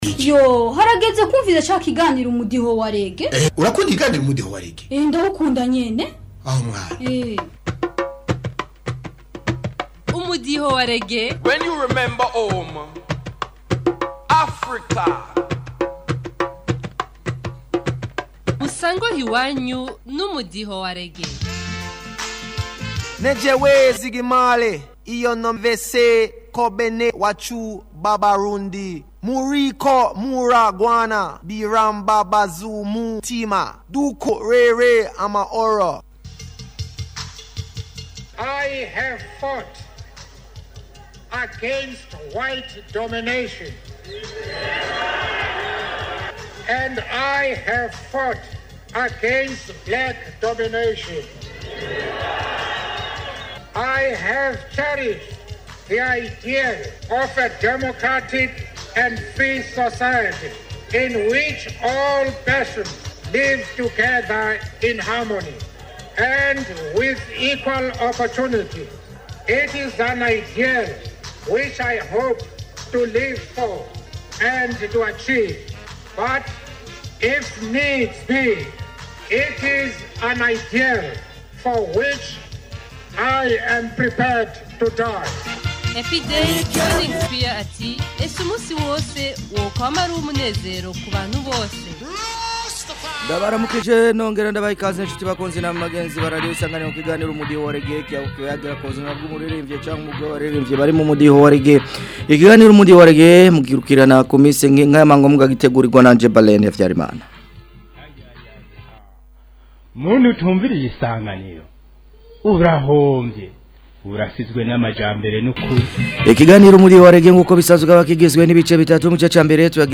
Umudiho wa Reggae